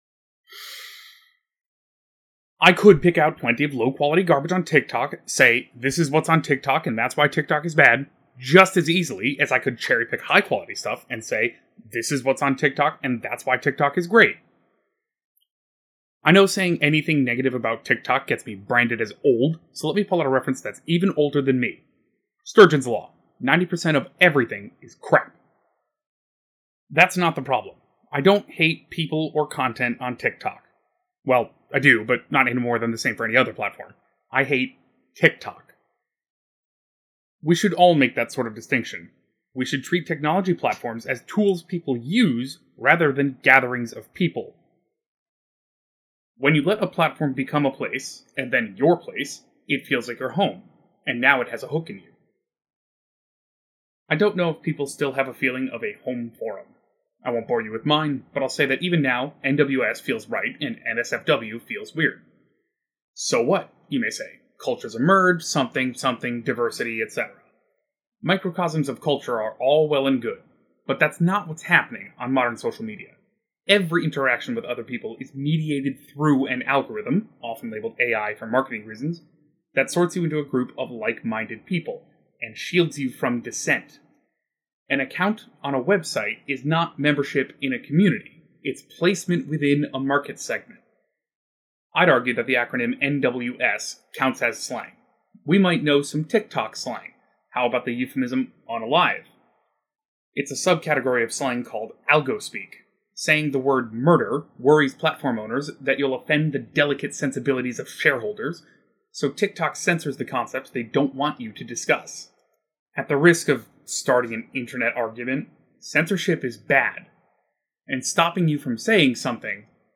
VO noise and some stuff removal